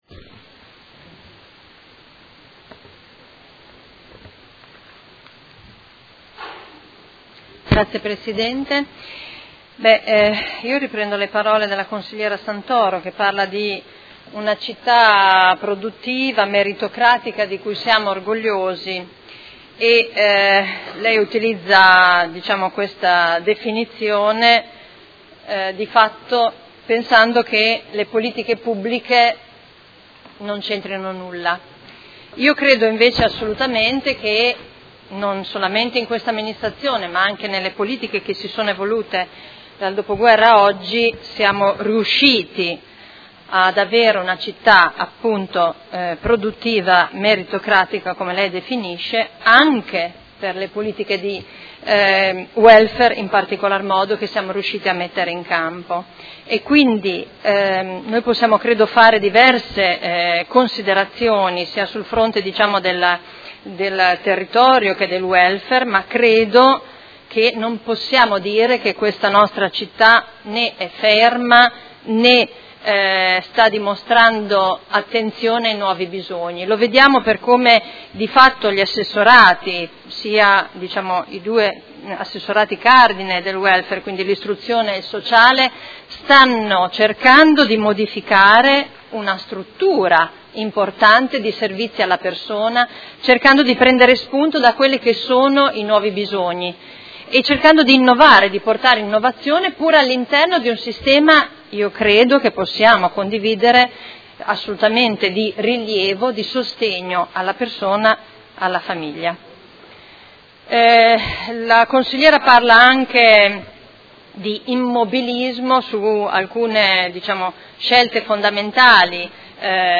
Seduta del 20/12/2018. Dibattito su delibera di bilancio, Ordini del Giorno, Mozioni ed emendamenti